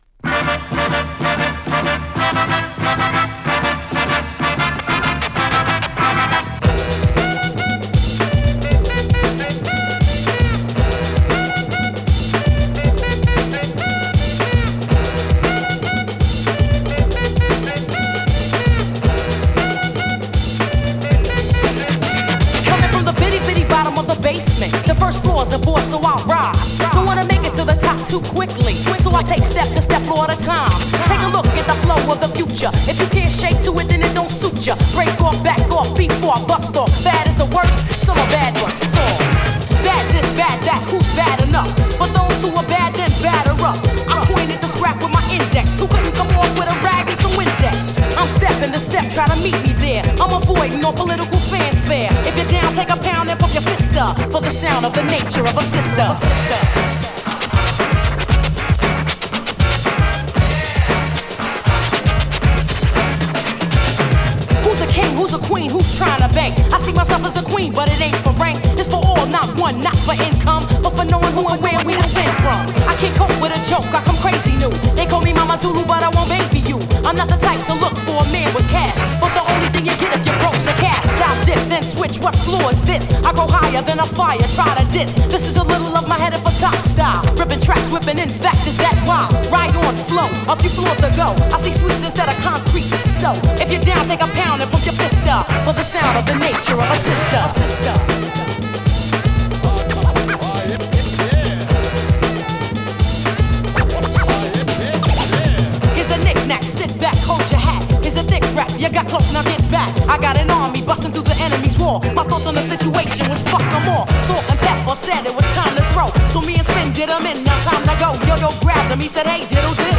1 返品特約に関する重要事項 お問い合わせ お気に入り登録 GROOVE感がたまらないクラシック作!!